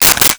Stapler 04
Stapler 04.wav